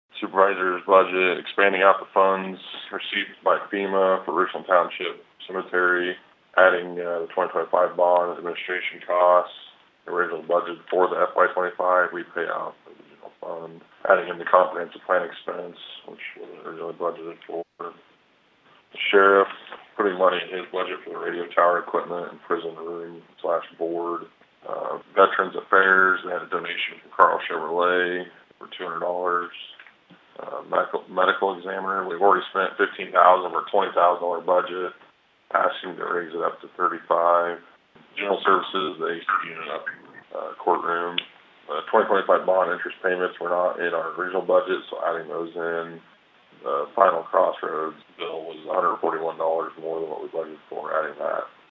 Board Chair Nathan Baier explained some of the amendments…